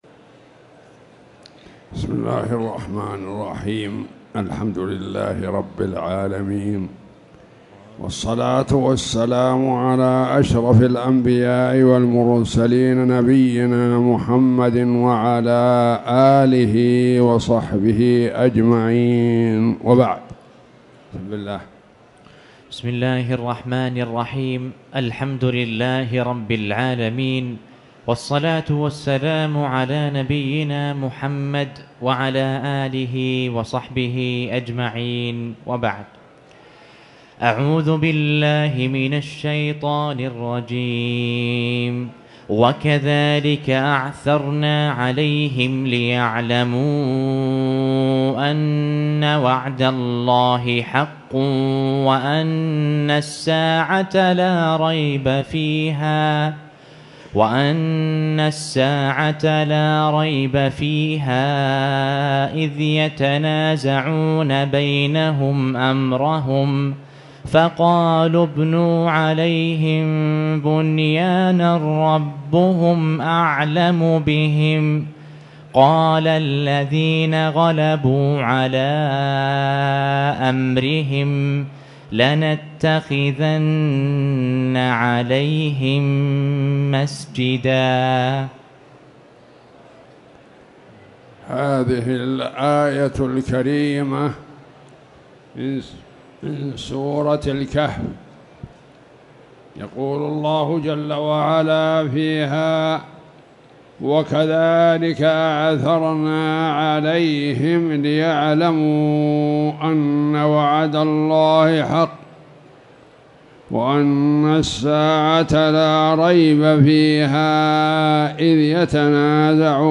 تاريخ النشر ١ رجب ١٤٣٨ هـ المكان: المسجد الحرام الشيخ